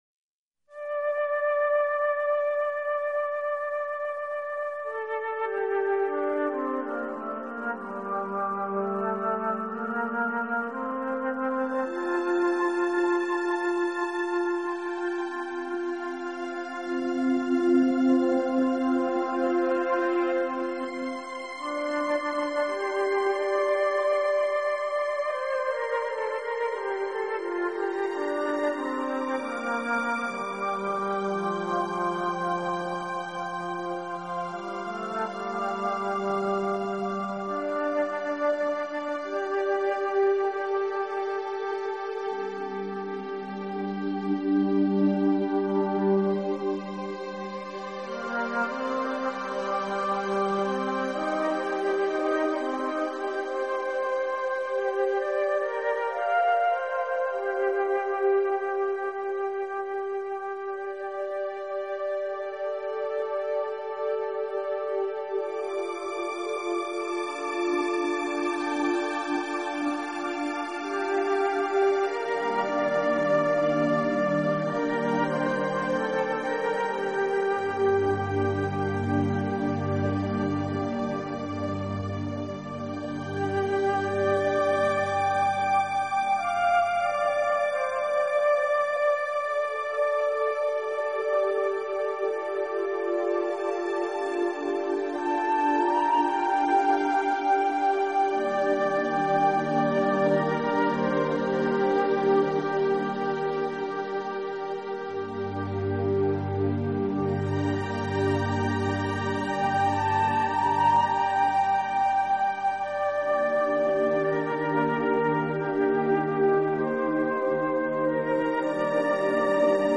旋律柔和轻松，泡一杯茶，翻开一本你喜爱的书，徜徉在音乐陪伴的舒缓生活里。